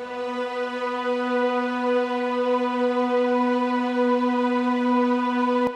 piano-sounds-dev
b5.wav